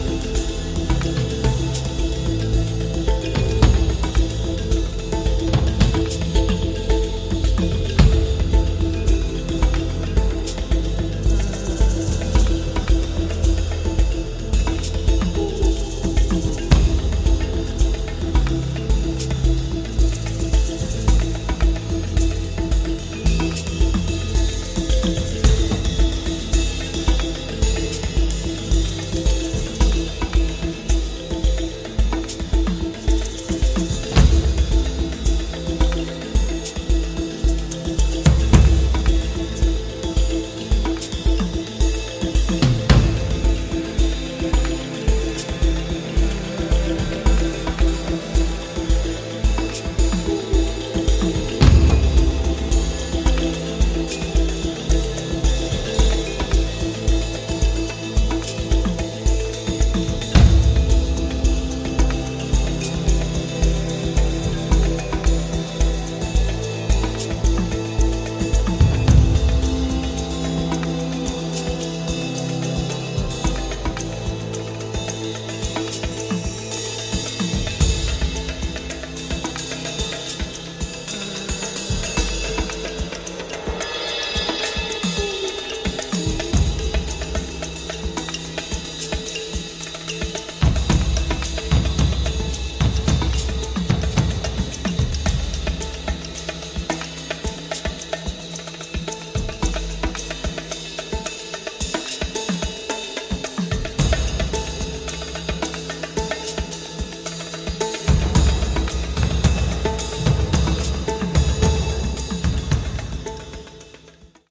African Drums and Percussion
Acoustic Piano, Keyboards and Drum Programming